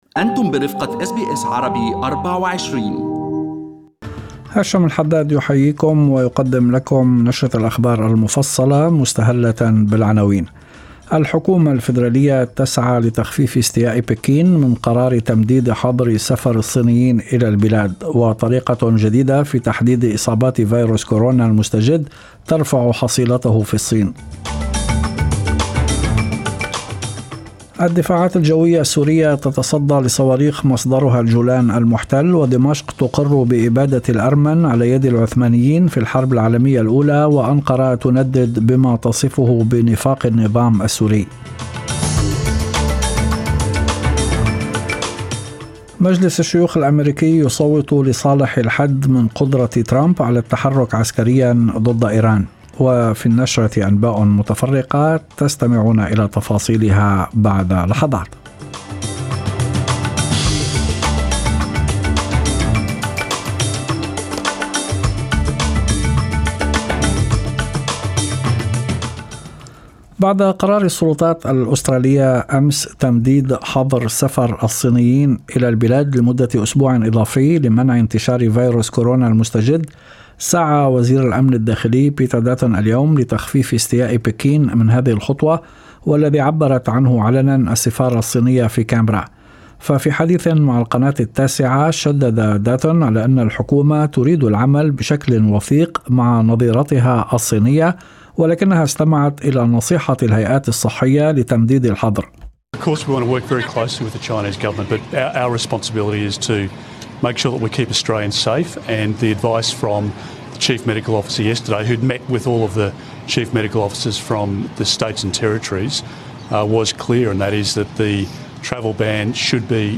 نشرة أخبار المساء 14/02/2020
Arabic News Bulletin Source: SBS Arabic24